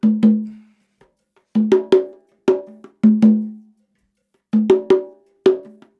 80 CONGA 2.wav